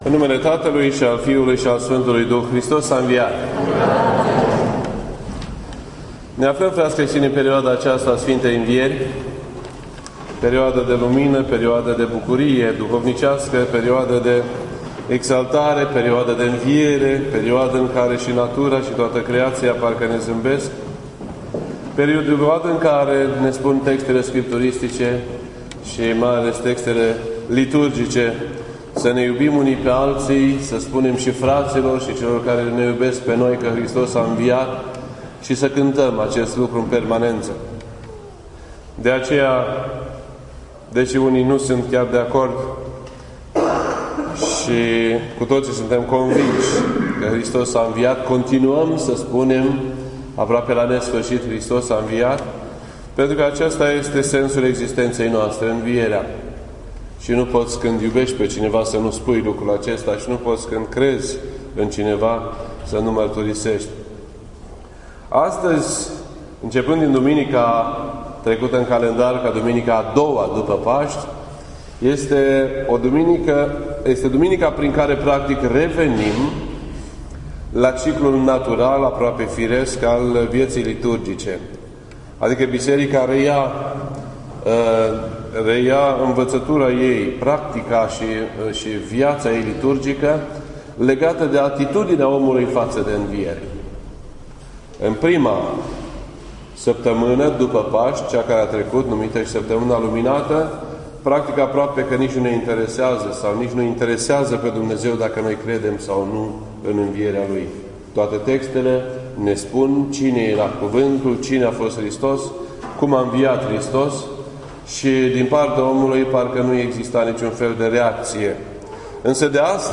This entry was posted on Sunday, April 19th, 2015 at 3:55 PM and is filed under Predici ortodoxe in format audio.